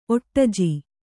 ♪ oṭṭaji